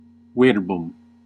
Ääntäminen
US : IPA : [wɝd] UK : IPA : /wɜː(ɹ)d/